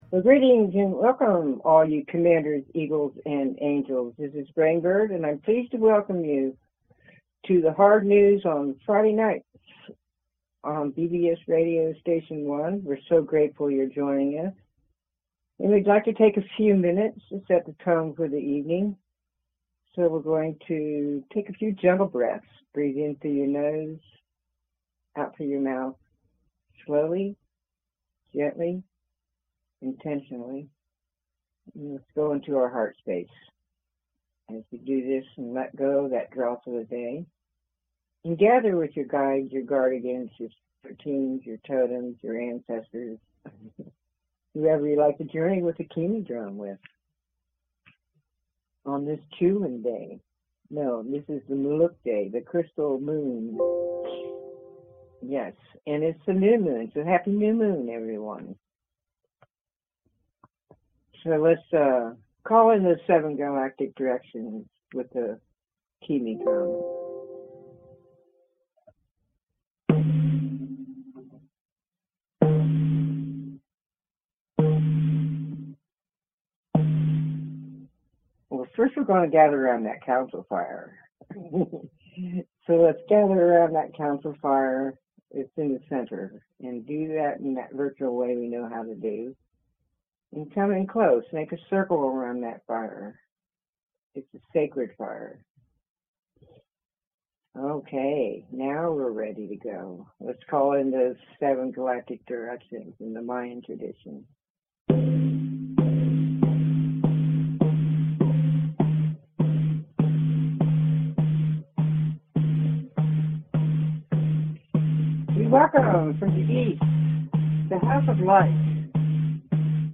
Talk Show Episode, Audio Podcast, Hard News on Friday and Cosmic Awakening, The Mercury-Pluto Portal and the Resurrection Flame on , show guests , about Cosmic Awakening,Mercury-Pluto Portal,the Resurrection Flame,Great Ascension Portal,Celestial Alignments,PanSTARRS Comet,Aries,Gemini,Scorpio,Spiritual Healing, categorized as History,News,Paranormal,Physics & Metaphysics,Politics & Government,Society and Culture,Spiritual,Medium & Channeling,Theory & Conspiracy